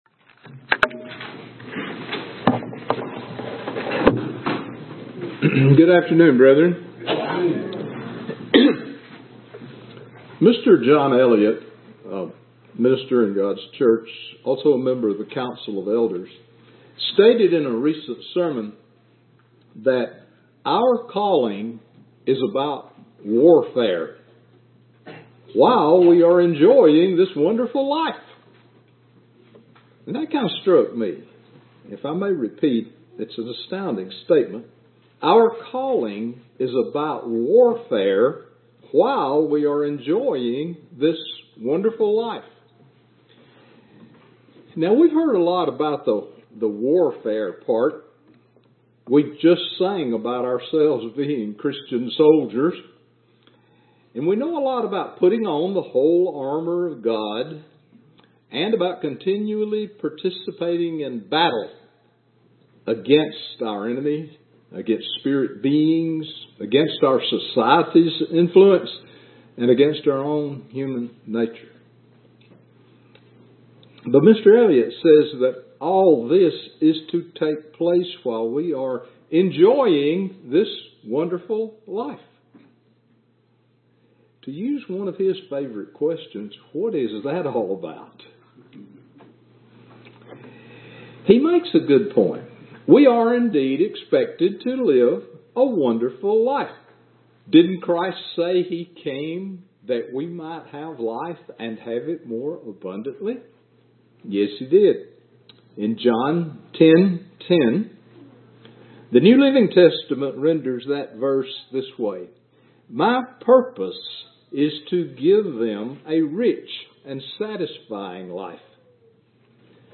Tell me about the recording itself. Given in Birmingham, AL